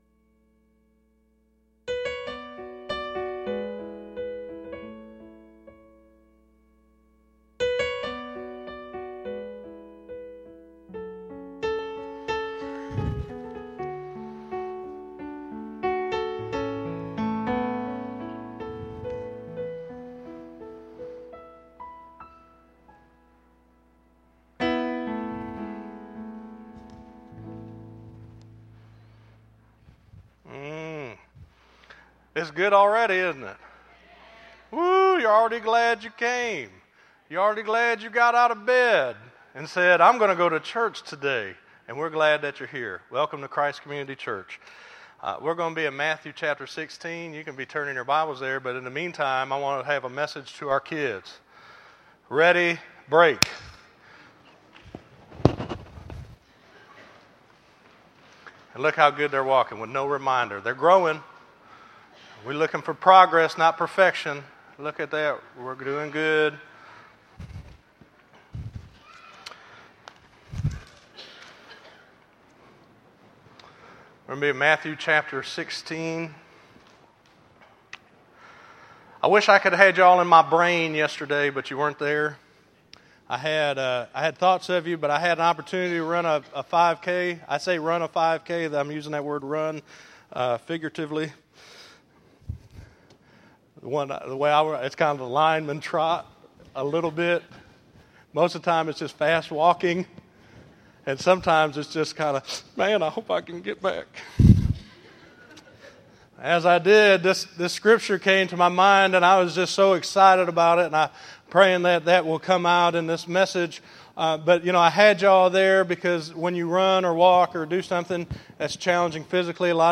8_25_13_Sermon.mp3